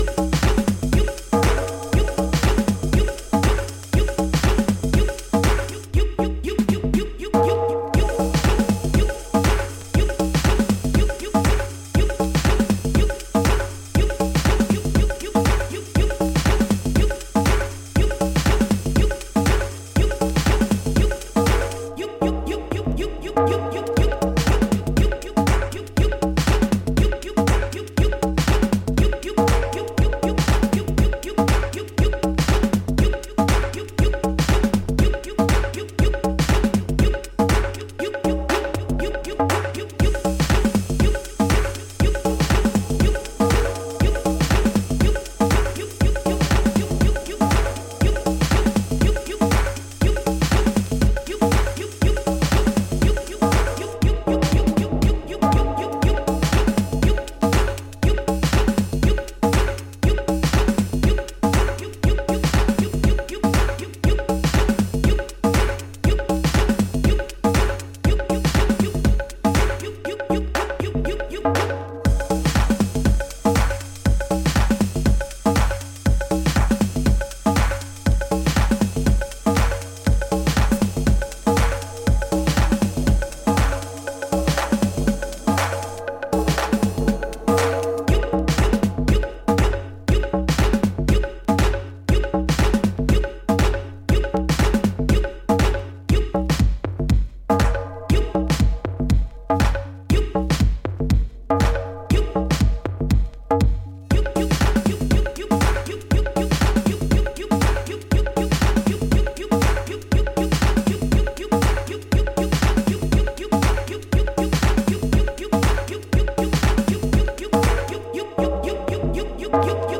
laidback, effects-laden, mind-altering acid headiness
whose angular TB-303 bassline is superb.